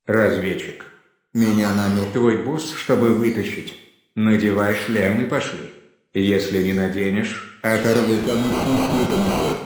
Спустя немного времени из кпк раздался синтезированный голос: